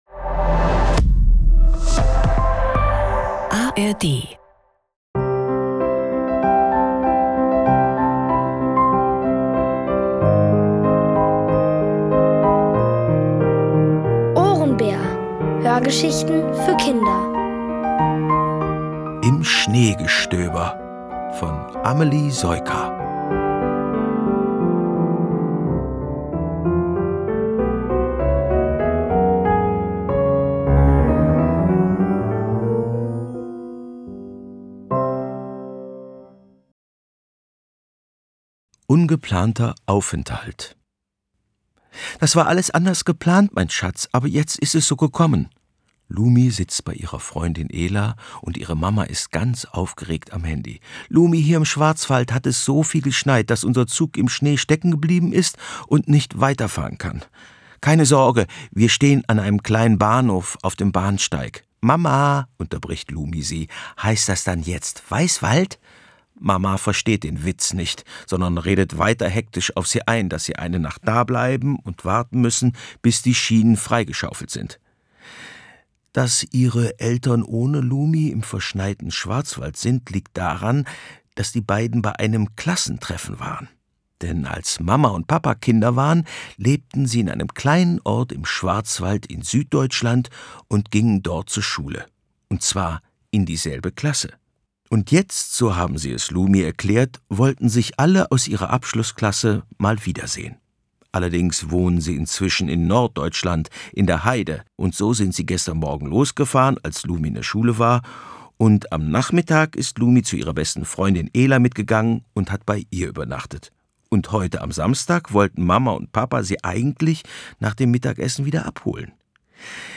Diesmal erzählt Opa von Ururgroßvater August, der als Landbriefträger von einem heftigen Schneefall überrascht wurde – mitten im Wald! Alle 5 Folgen der OHRENBÄR-Hörgeschichte: Im Schneegestöber von Amelie Soyka. Es liest: Bernhard Schütz.